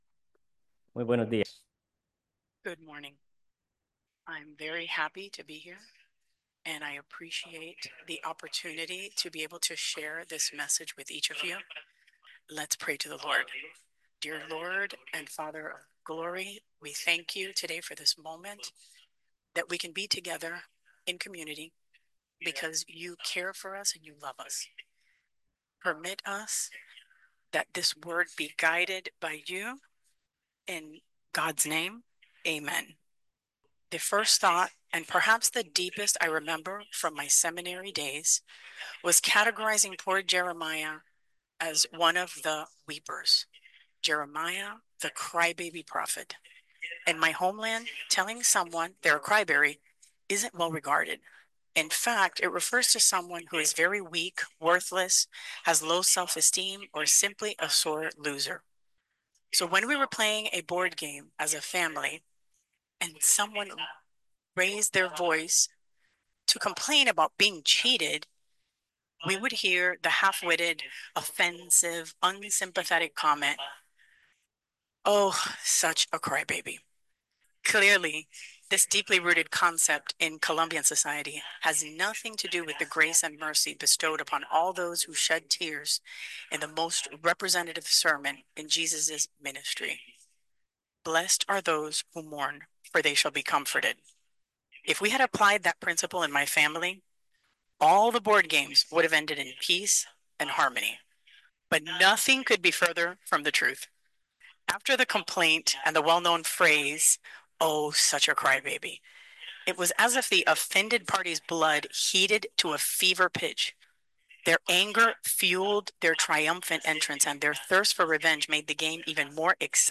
The English voice you will hear is that of the live translator in the sanctuary.